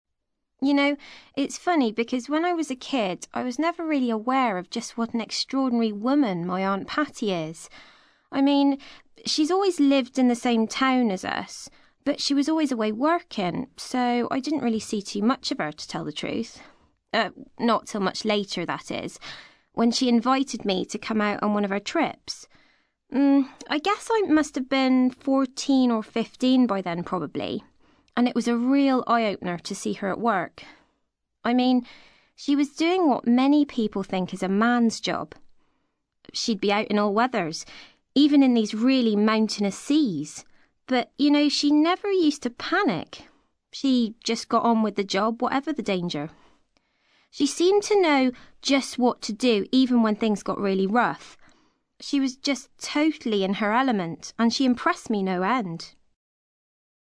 ACTIVITY 60: You will hear five short extracts in which five people are talking about a member of their family who they admire.